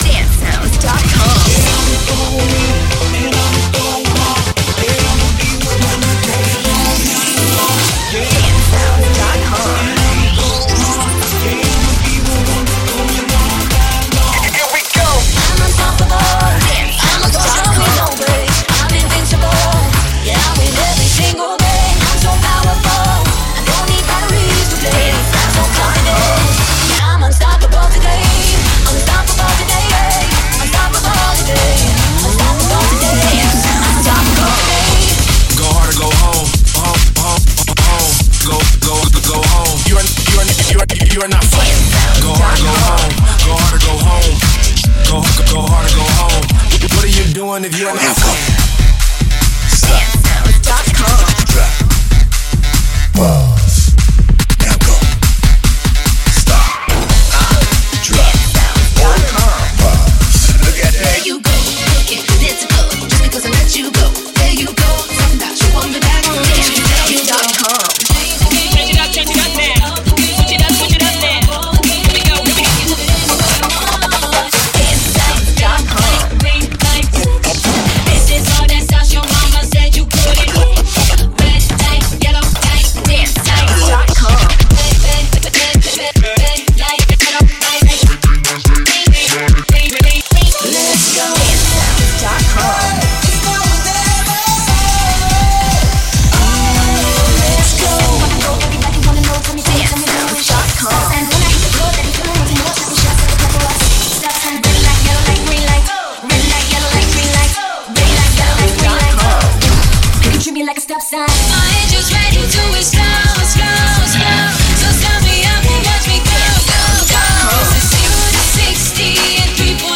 Premade Dance Music Mix